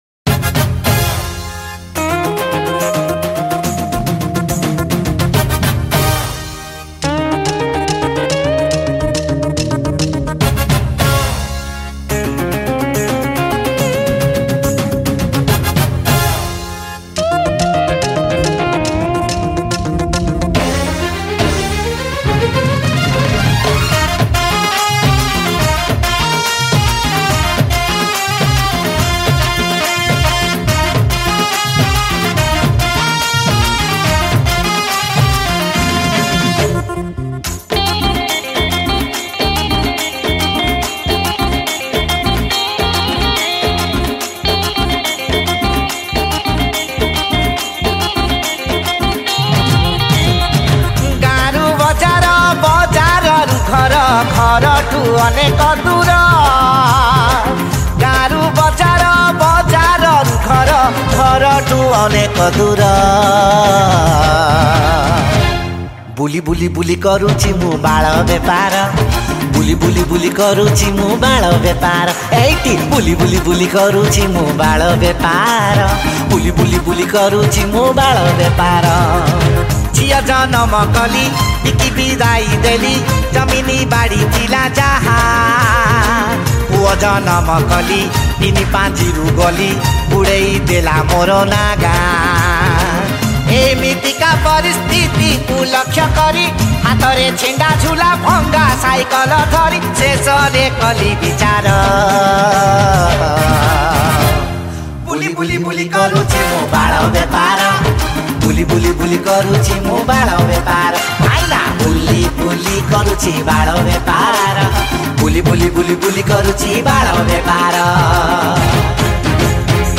Drum